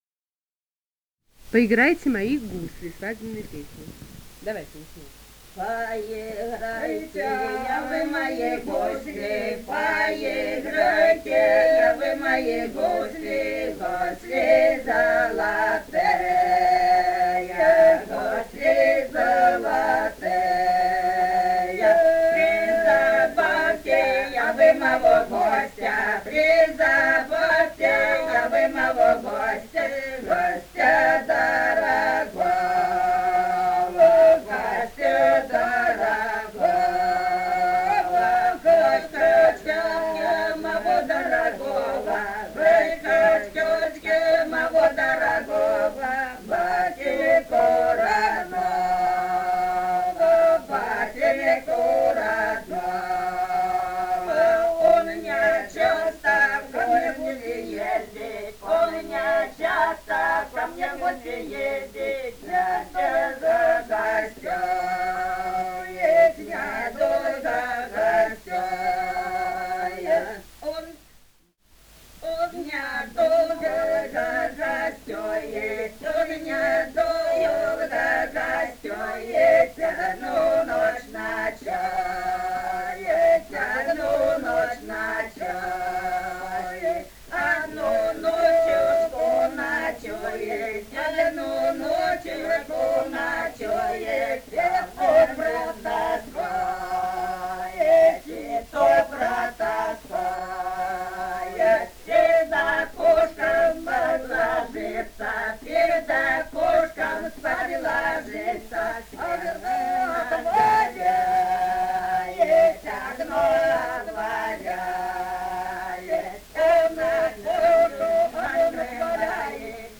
полевые материалы
Ростовская область, ст. Вёшенская, 1966 г. И0938-10